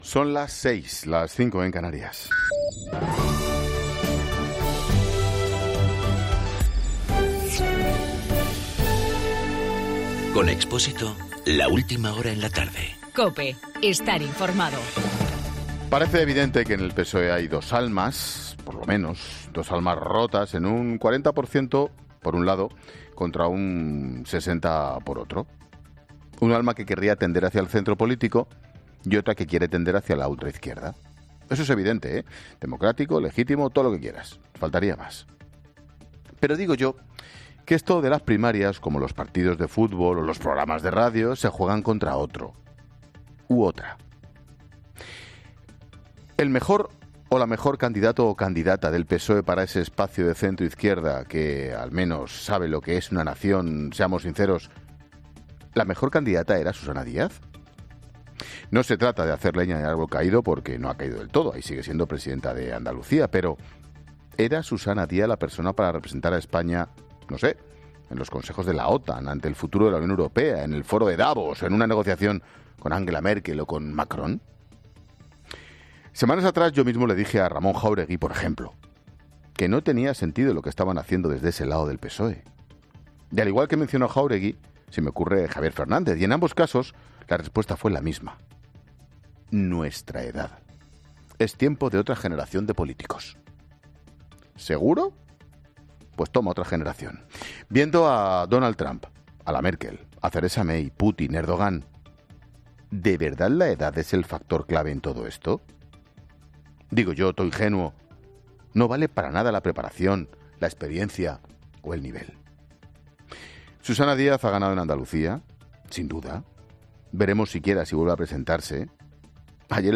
AUDIO: Monólogo 18 h.